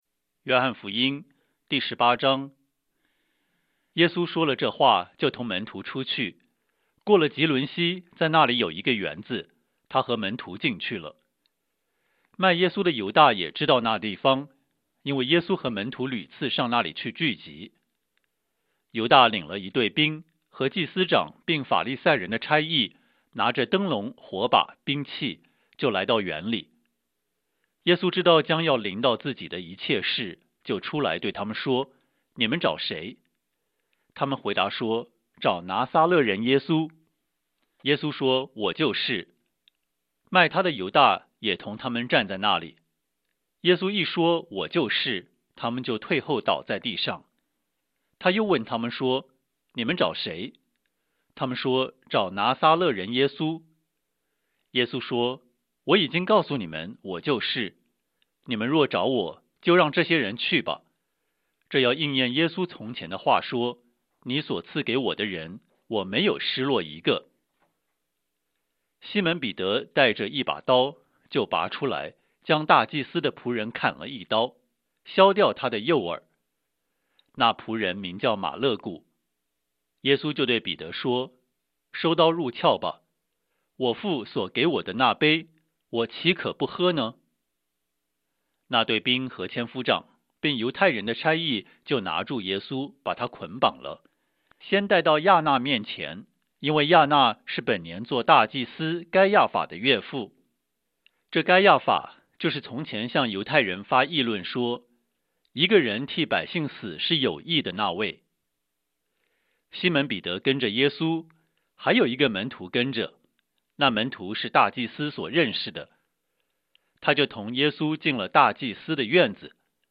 圣经， 圣经朗读